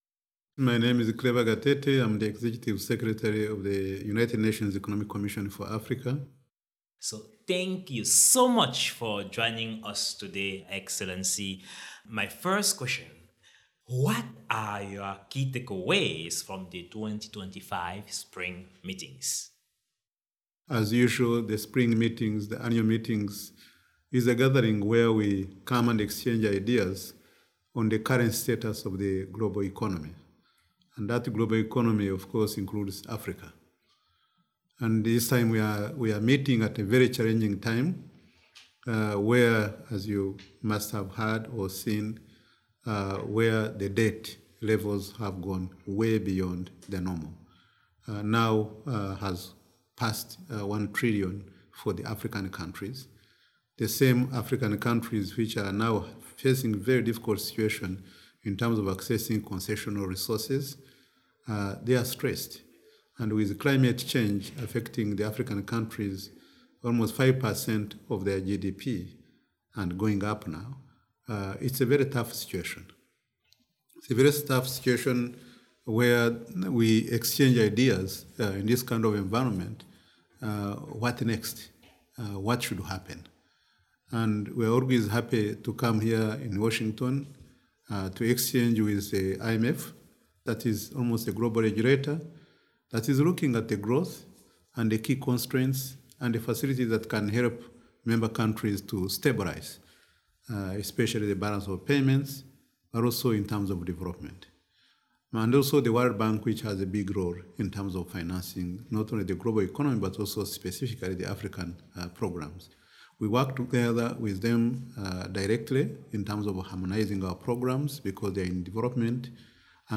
Foresight Africa podcast at the 2025 World Bank/IMF Spring Meetings